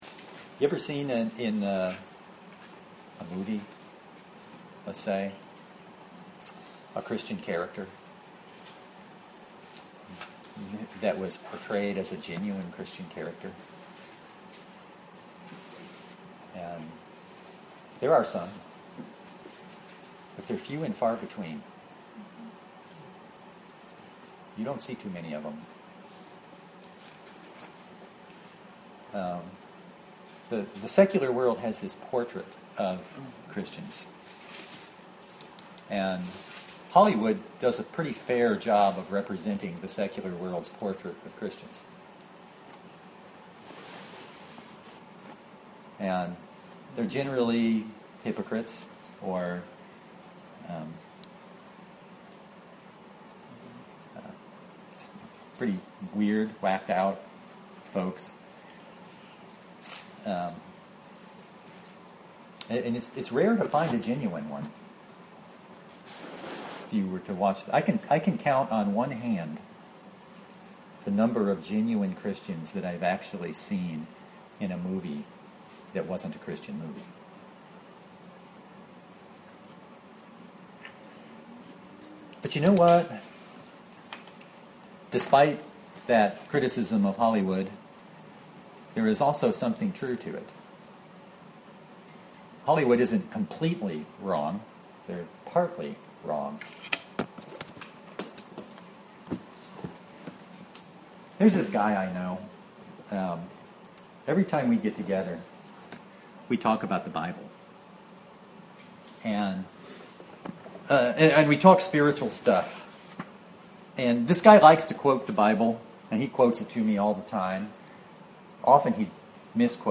Judges 17:1-13 Service Type: Sunday Bible Text